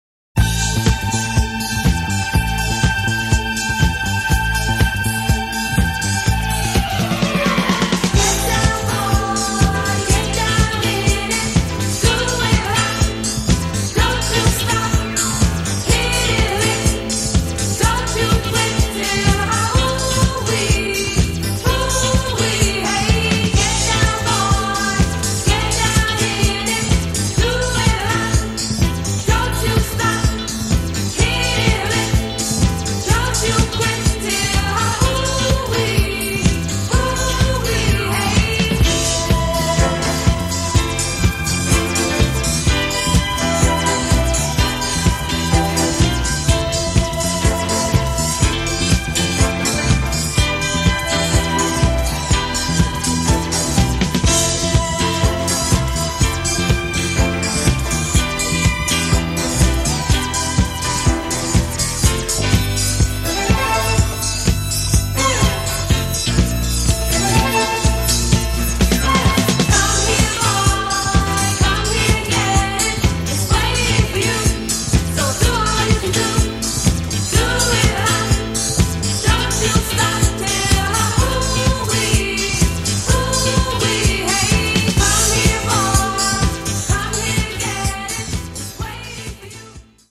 4 monstruous tracks straight from the basement